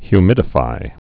(hy-mĭdə-fī)